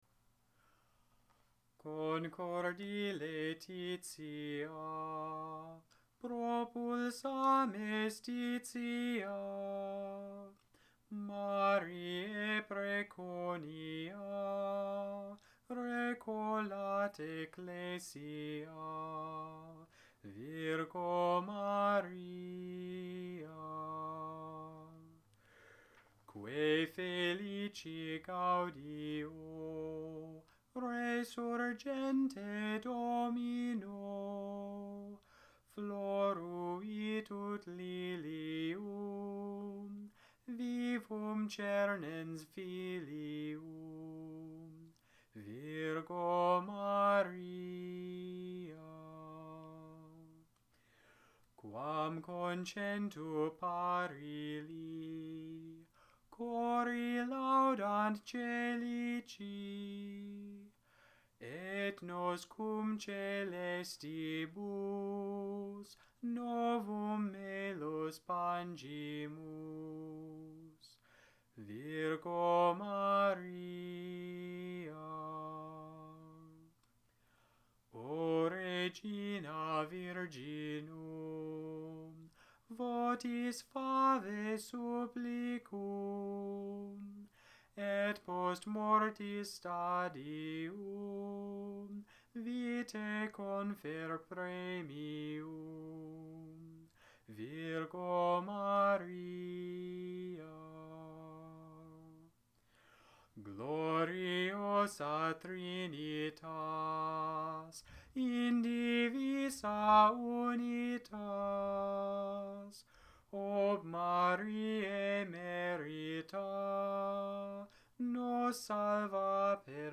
Gregorian chant audios